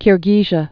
(kîr-gēzhə, -zhē-ə)